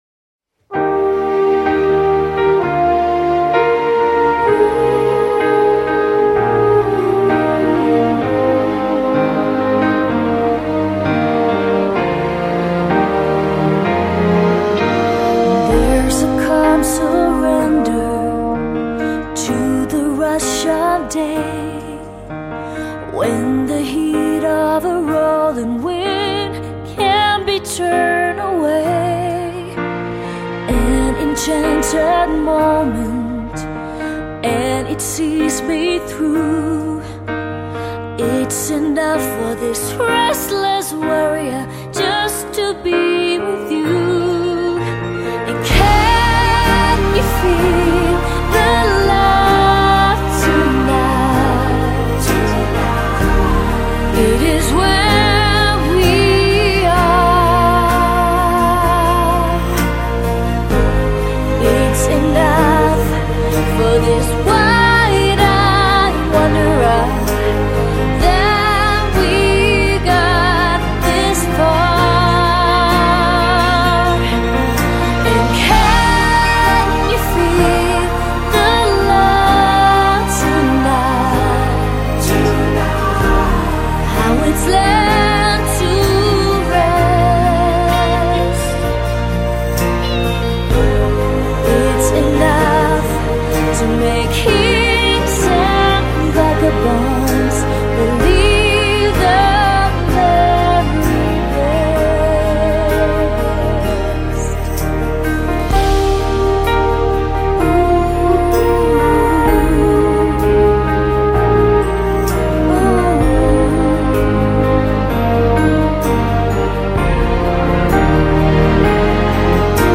这首专辑中以翻唱经典为主，同时糅合了她自己独特的风格，有一种使经典重生和耳目一新的感觉。